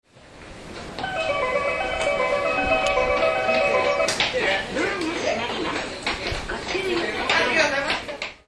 周辺を配慮してか、メロディーの音量が非常に小さいです。
なお、2005年12月に自動放送の言い回しを変更、声が変更されました。
Gota del Vient 音量小さめ